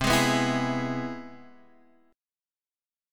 C+M7 chord